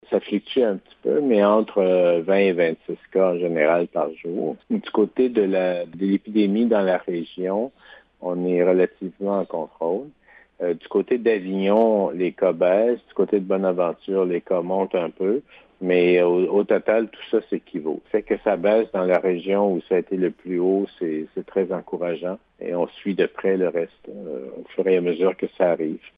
Le directeur de la Santé publique, le Dr Yv Bonnier-Viger, affirme que malgré ces chiffres, la région semble avoir atteint un plateau et si cette tendance se maintient, il pourrait y avoir une baisse du nombre de cas dans les prochaines semaines: